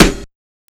SNARE 81.wav